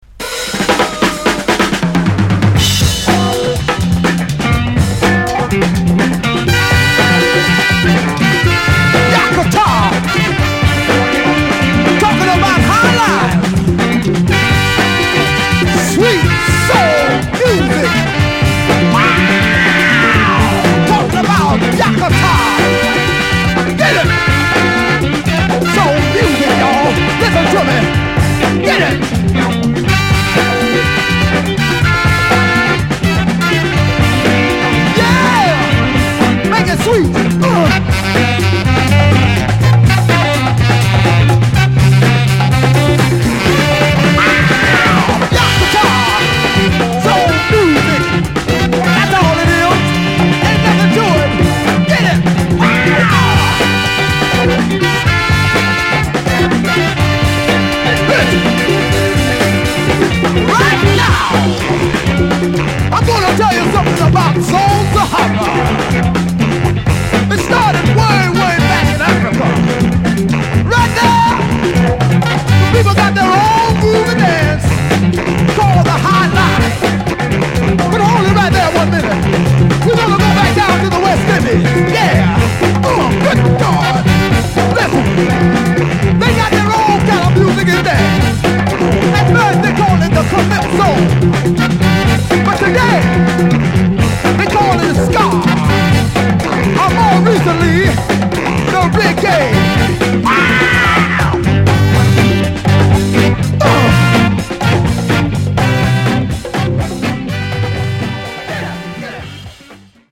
パーカッシヴな濃厚SOUL&FUNK